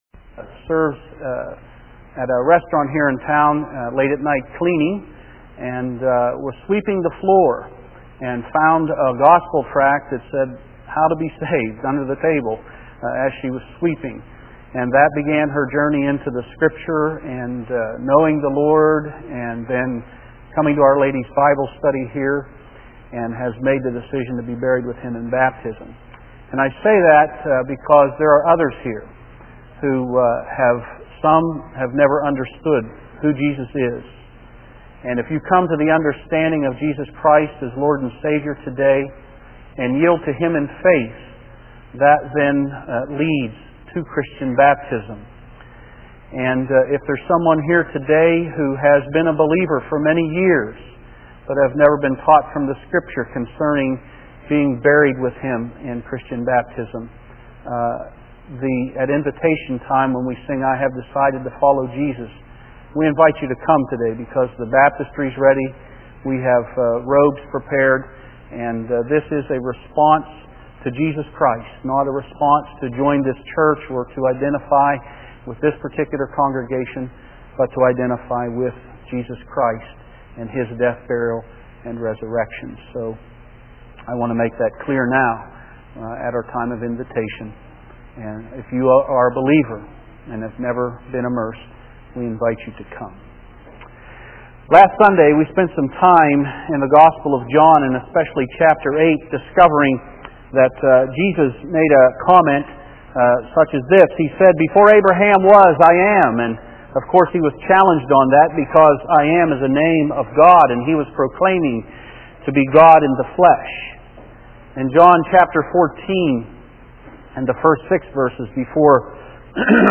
Sermons – North Street Christian Church